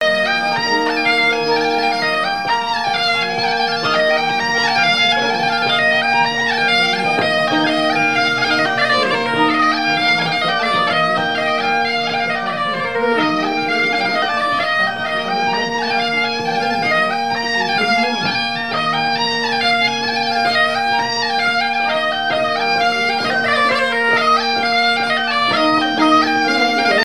danse : branle : courante, maraîchine
Airs joués à la veuze et au violon et deux grands'danses à Payré, en Bois-de-Céné
Pièce musicale inédite